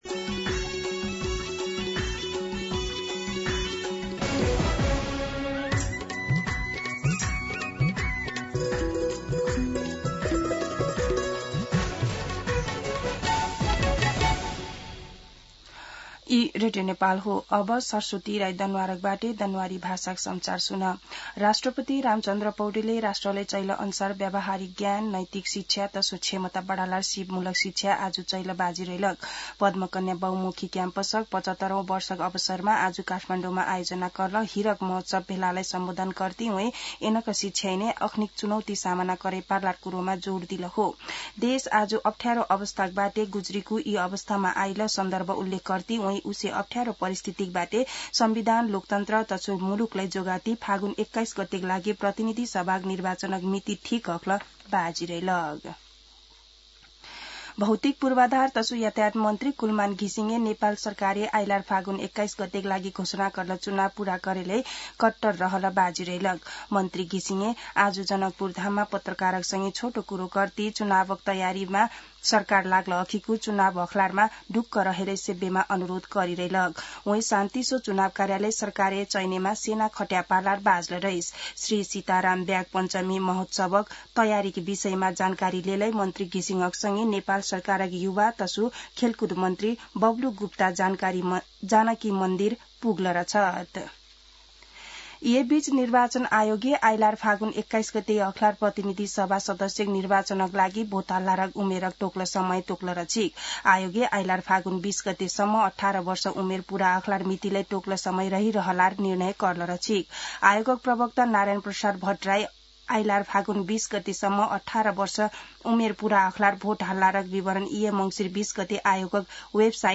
दनुवार भाषामा समाचार : ५ मंसिर , २०८२
Danuwar-News-08-5-.mp3